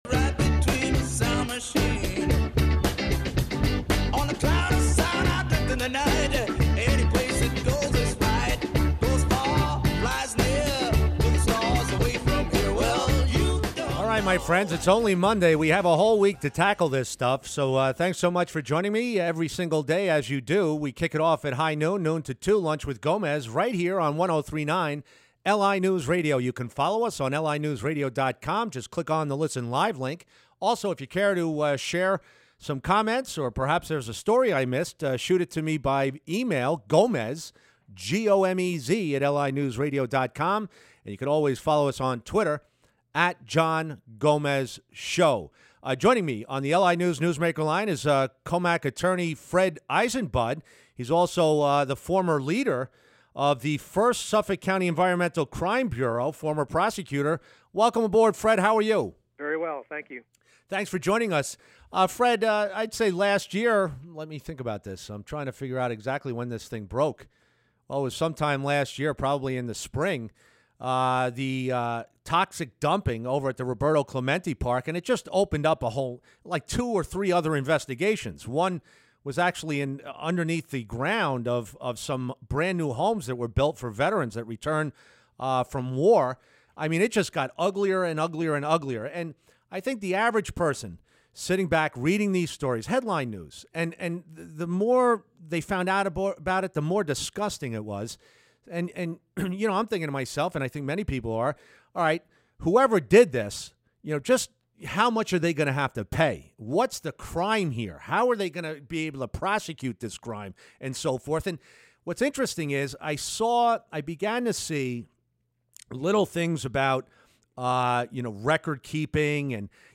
interviewed about environmental crimes